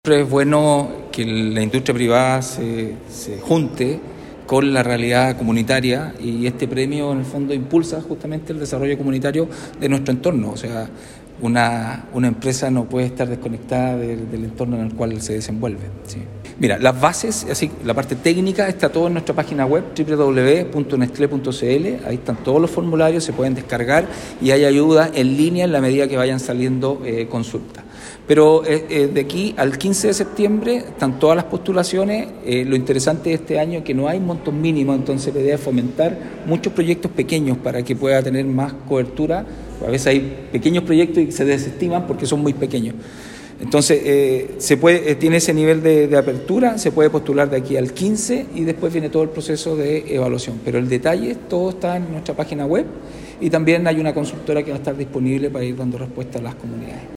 En la Sala de Sesiones de la Municipalidad de Osorno, se realizó el lanzamiento del Fondo de Desarrollo Local Henri Nestlé.